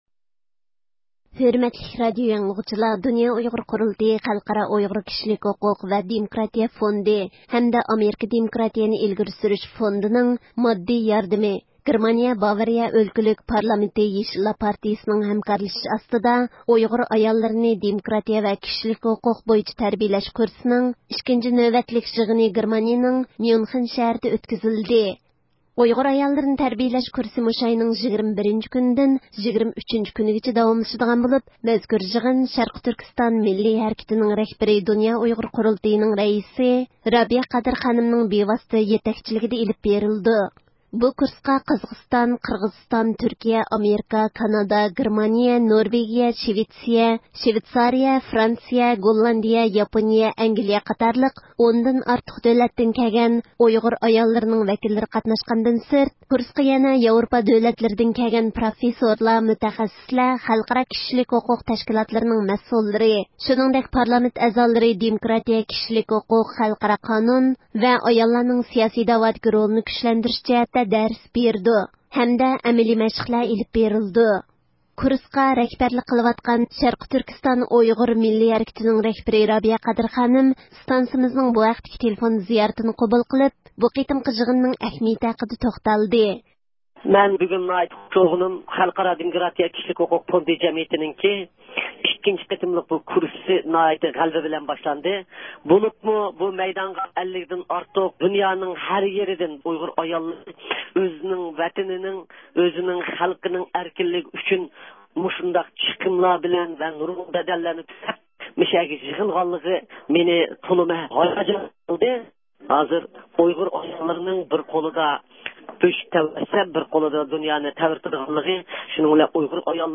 كۇرسقا رەھبەرلىك قىلىۋاتقان، شەرقىي تۈركىستان ئۇيغۇر مىللىي ھەرىكىتىنىڭ رەھبىرى رابىيە قادىر خانىم، ئىستانسىمىزنىڭ تېلېفۇن زىيارىتىنى قوبۇل قىلىپ، بۇ قېتىمقى يىغىننىڭ ئەھمىيىتى ھەققىدە توختالدى.